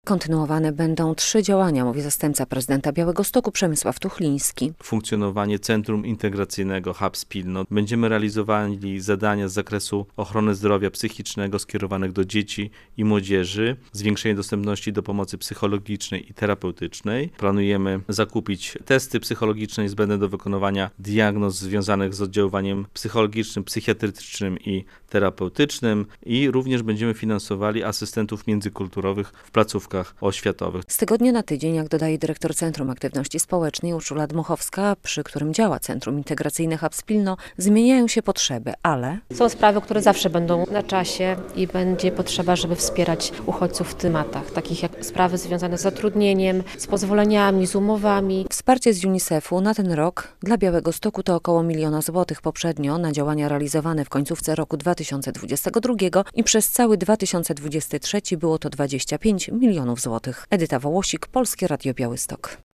UNICEF przekazał około miliona złotych do budżetu Białegostoku - relacja